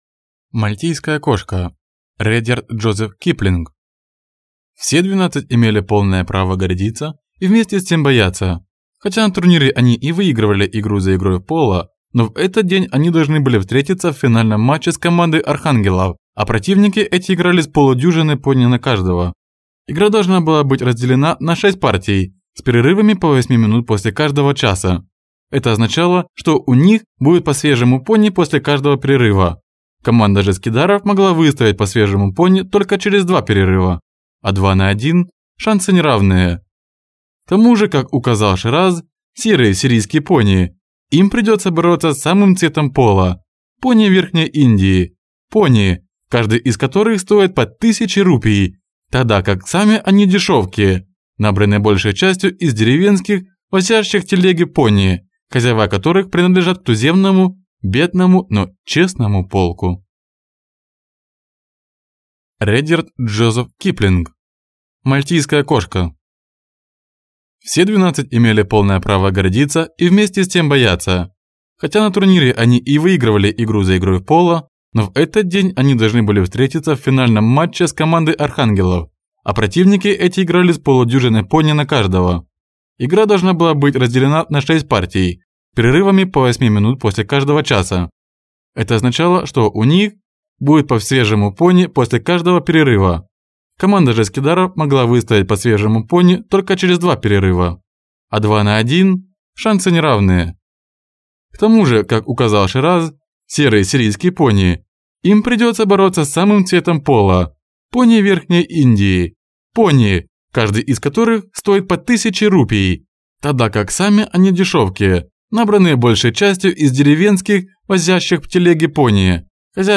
Аудиокнига Мальтийская кошка | Библиотека аудиокниг